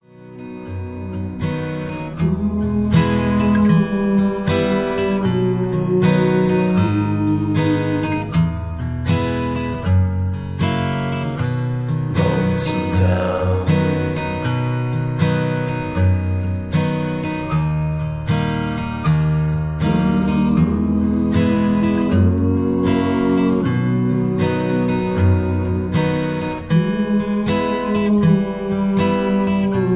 Key-B
Just Plain & Simply "GREAT MUSIC" (No Lyrics).